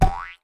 reward_drop_01.ogg